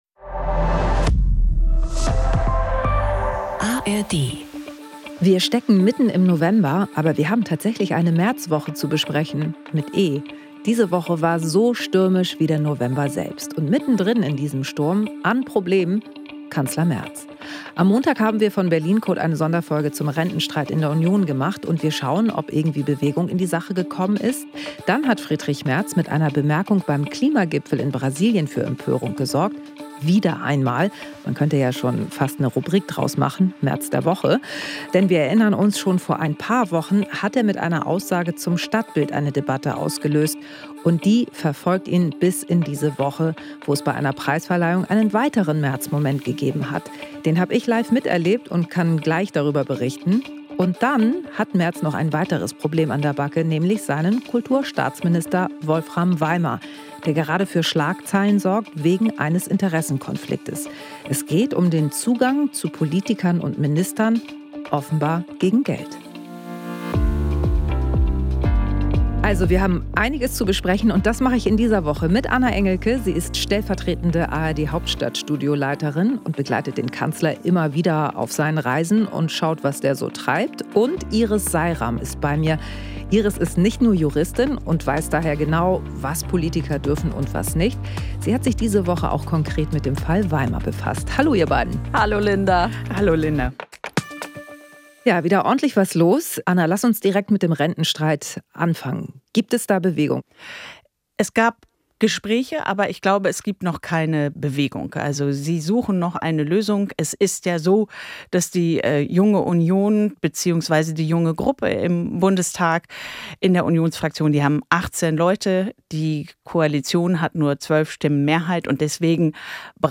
"Berlin Code" ist der Politik-Podcast aus dem ARD-Hauptstadtstudio. Linda Zervakis schaut mit den ARD-Korrespondentinnen und -korrespondenten jede Woche hinter die Kulissen der Bundespolitik.